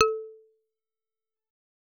content/hifi-public/sounds/Xylophone/A2.L.wav at main